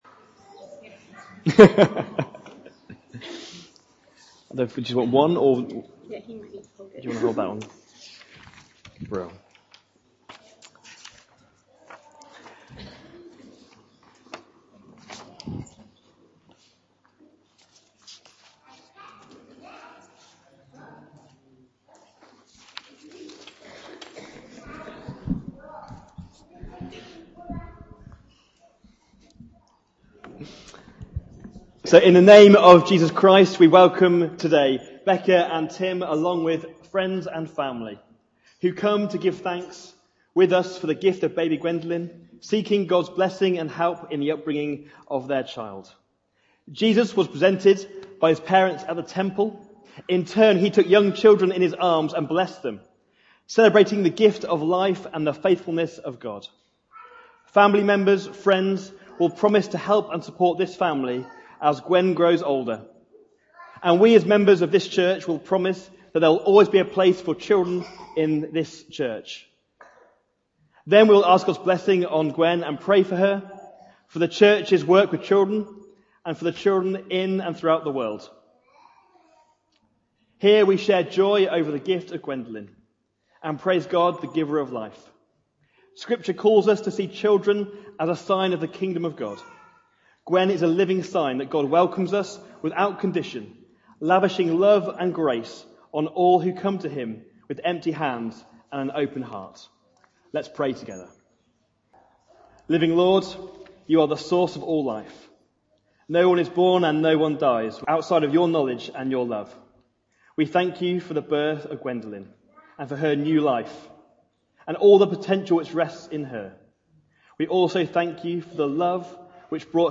Jul 21, 2019 The Woman at the Well MP3 SUBSCRIBE on iTunes(Podcast) Notes Discussion Sermons in this Series Reading - John 4 : 1 - 30 Includes Dedication, Sermon starts at 8:32 Loading Discusson...